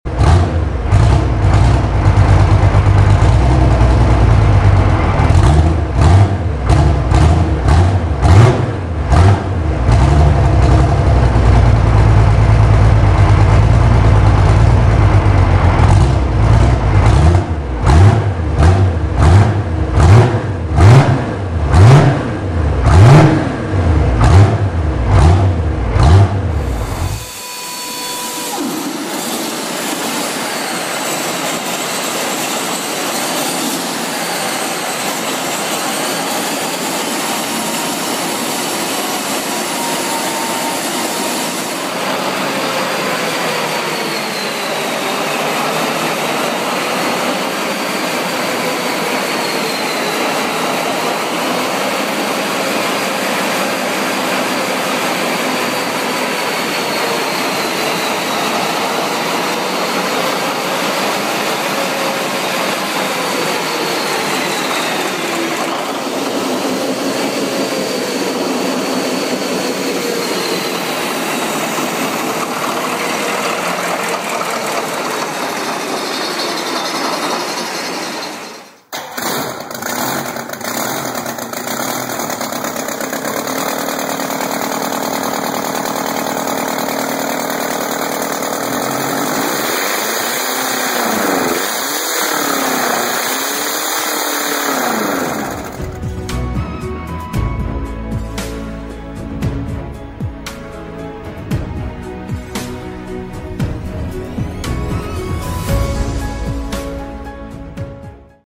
Big Crazy Old Engines Start sound effects free download
Big Crazy Old Engines Start Up Sound That Will Blow Your Mind